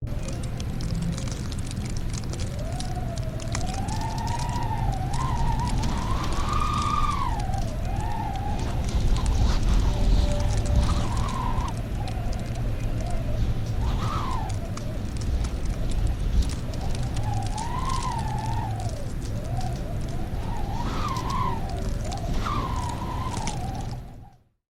Free Nature sound effect: Sleet In A Blizzard.
Sleet In A Blizzard
Sleet in a Blizzard.mp3